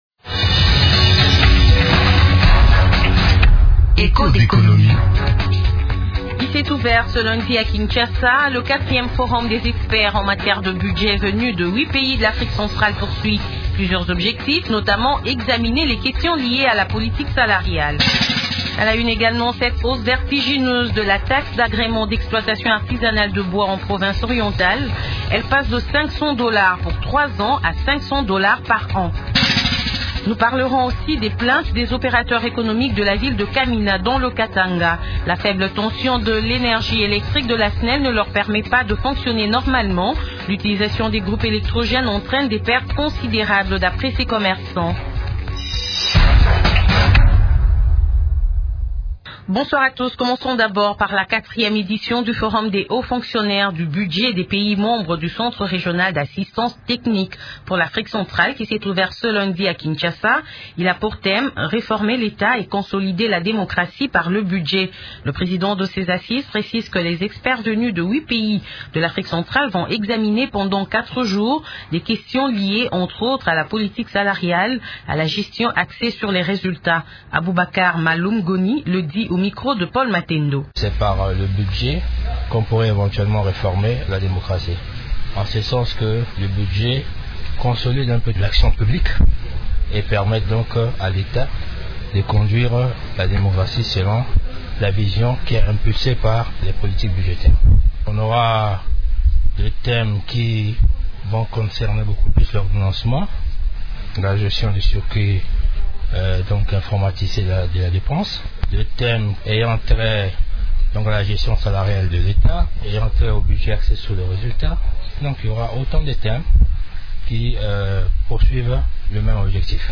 Les exploitants artisanaux du bois se plaignent dans l’émission de ce jour.